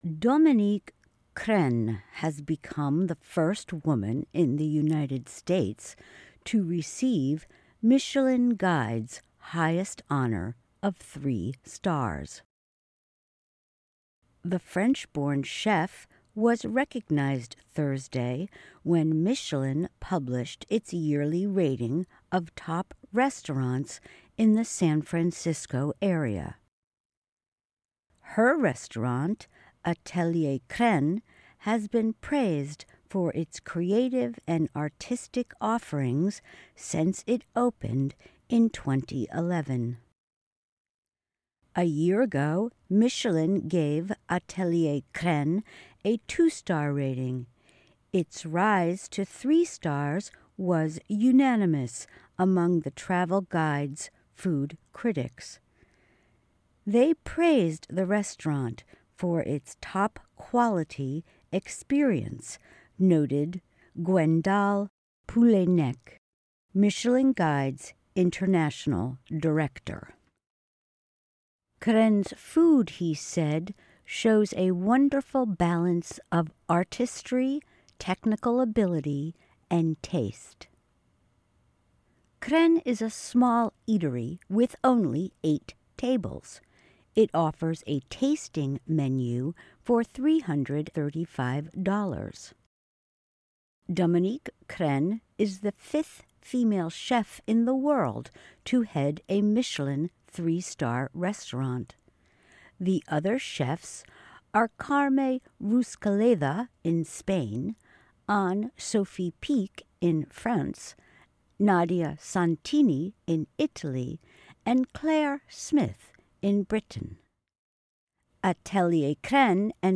News
慢速英语:米其林评出全美首位三星女厨师